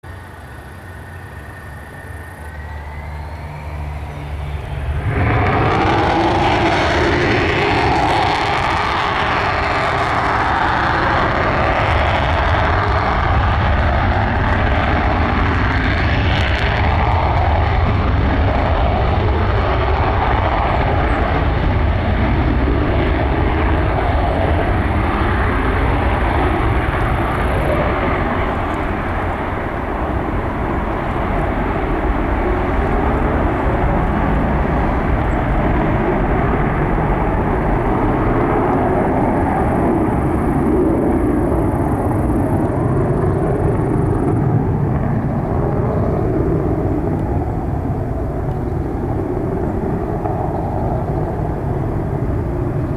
Звуки истребителя
Звук взлета истребителя (МиГ-31) (00:53)